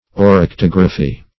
Search Result for " oryctography" : The Collaborative International Dictionary of English v.0.48: Oryctography \Or`yc*tog"ra*phy\, n. [Gr.